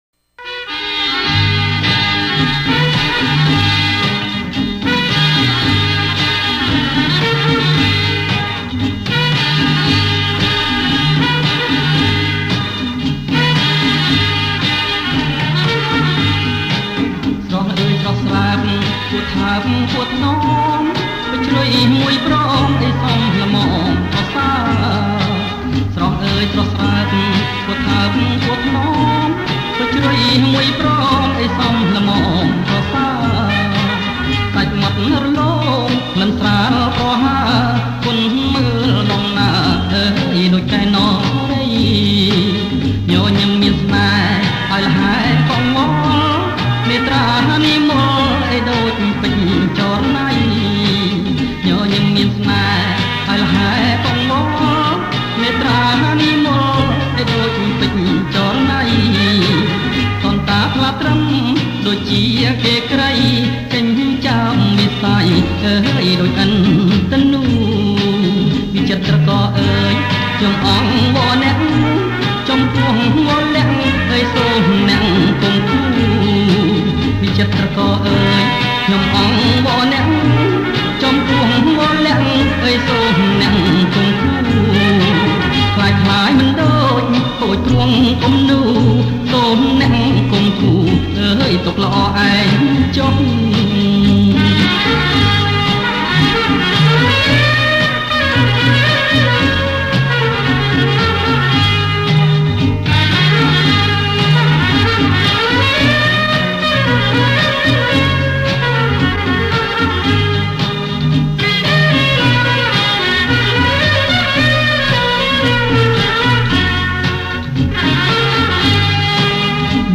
• ប្រគំជាចង្វាក់ សារ៉ាវ៉ាន់